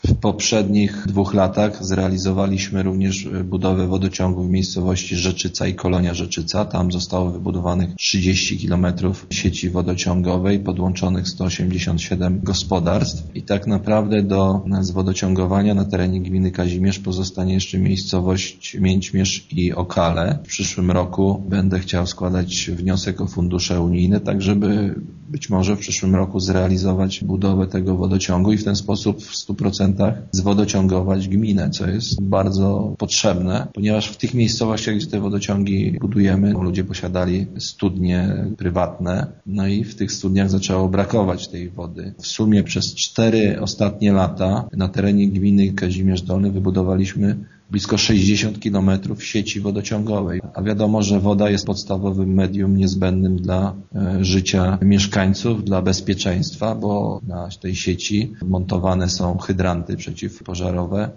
To kolejna taka inwestycja w gminie – przypomina burmistrz Kazimierza Dolnego Grzegorz Dunia: